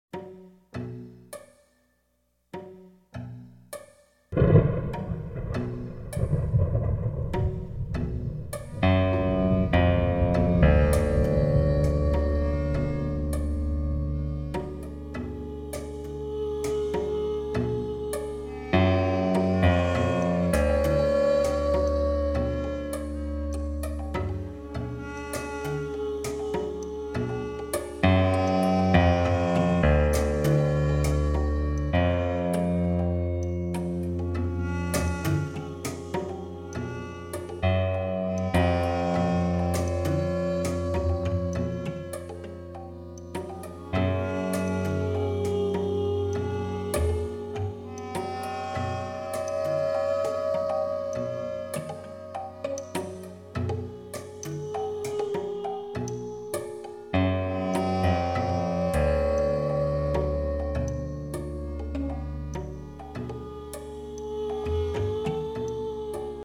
sampled electric guitar, thunder and percussion
vocal and harmonica samples